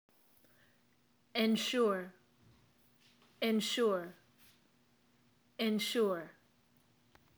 Read on for definitions, example sentences, and mp3s for pronunciation of three easily confused English verbs: assure, insure, and ensure.